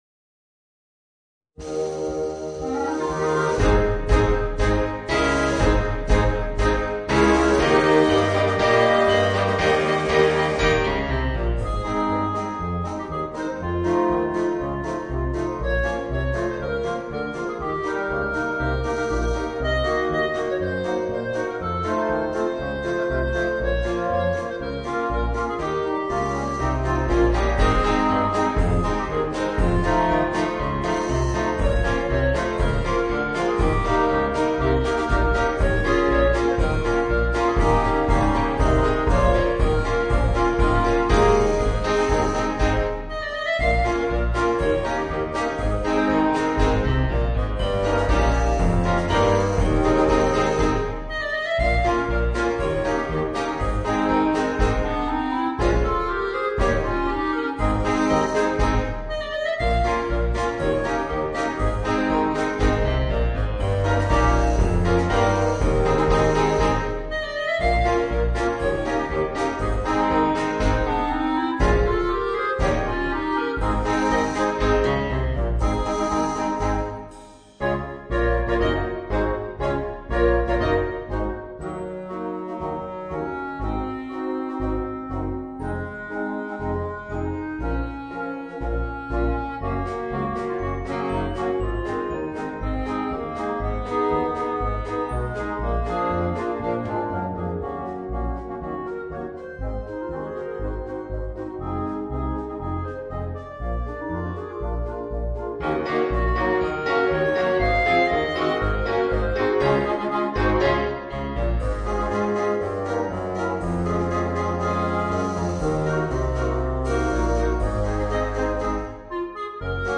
Voicing: Woodwind Quintet and Rhythm Section